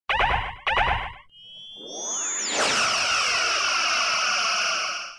phaser.wav